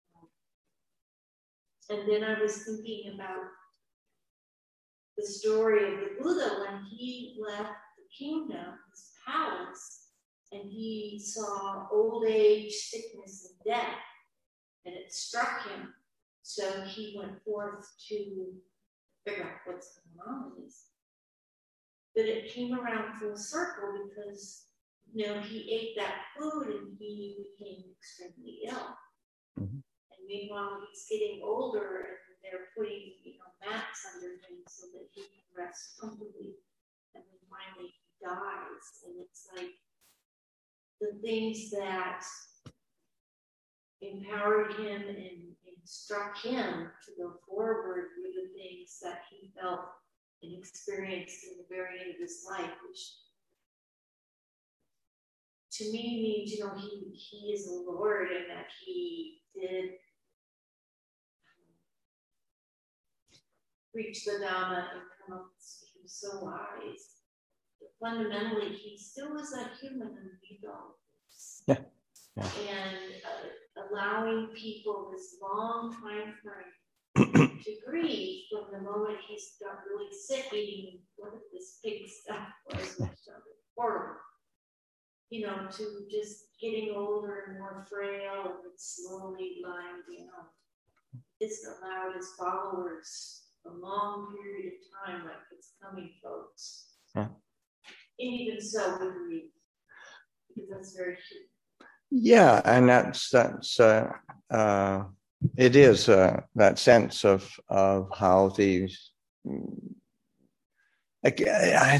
Online from Abhayagiri Buddhist Monastery in Redwood Valley, California